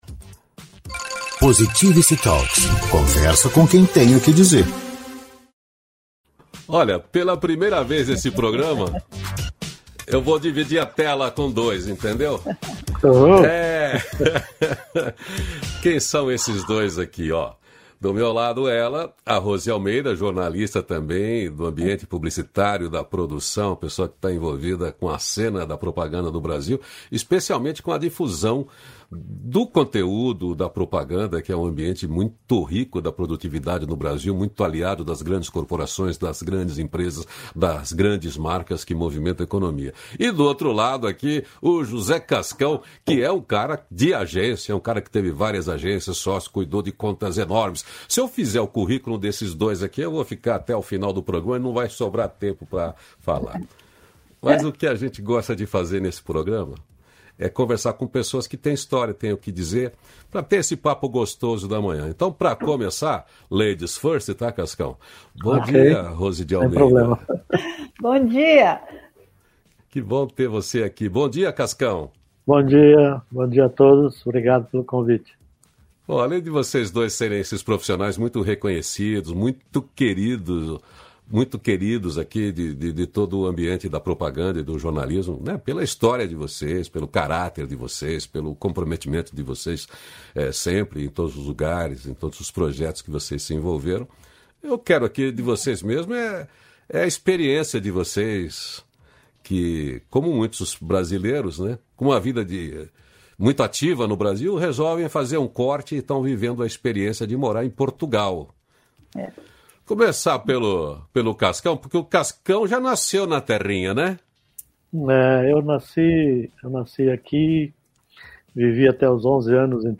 313-feliz-dia-novo-entrevista.mp3